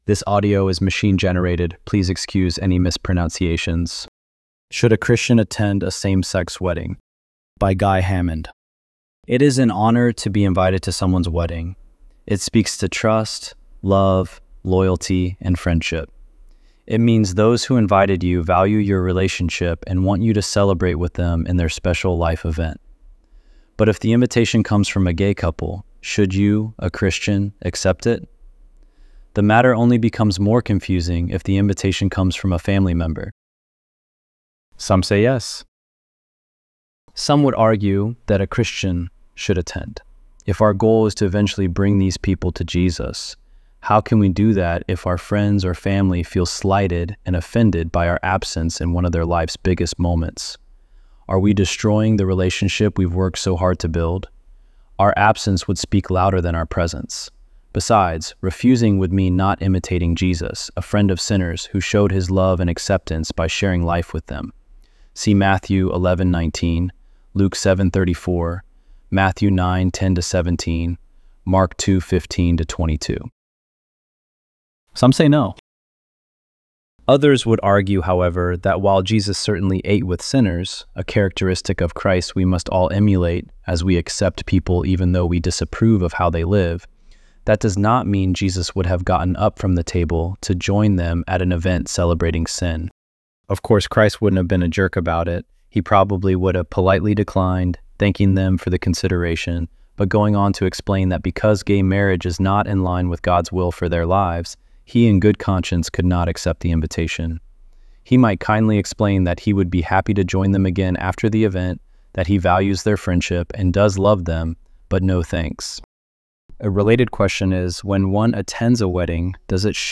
ElevenLabs_4_2.mp3